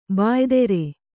♪ bāydere